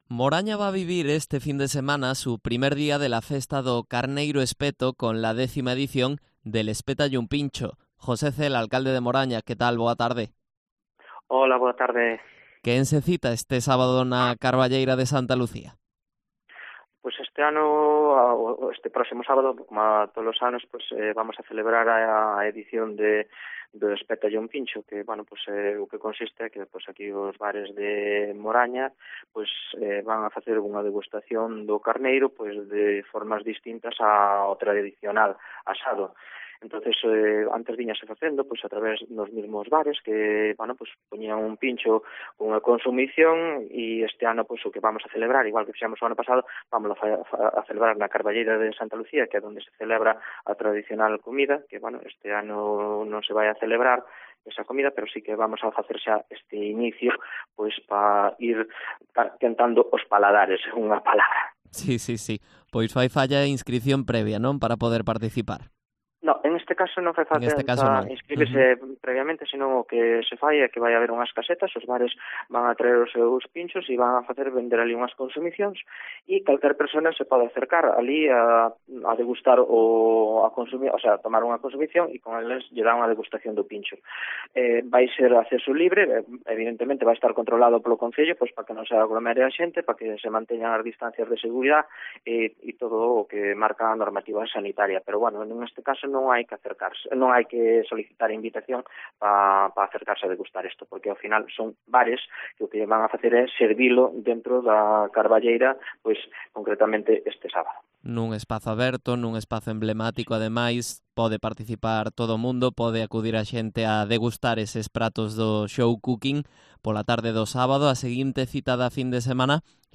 Entrevista a José Cela, alcalde de Moraña, en Herrera en Cope